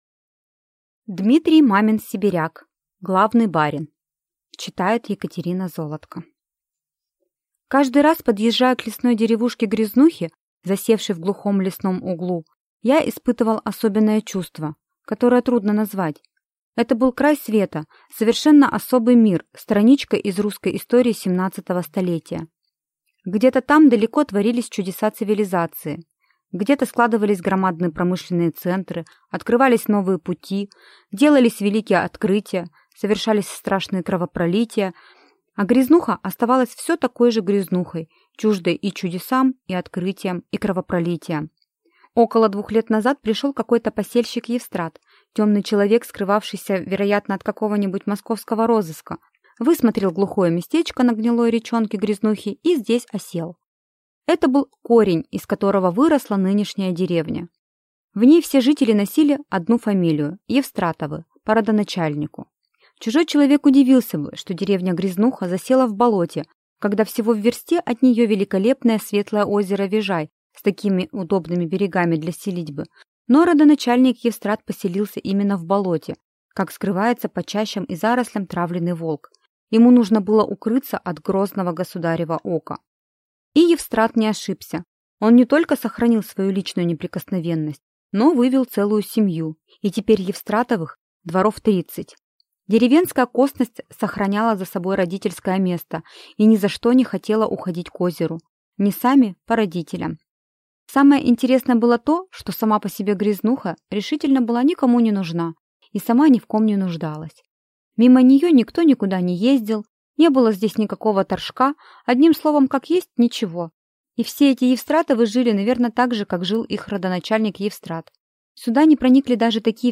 Aудиокнига Главный барин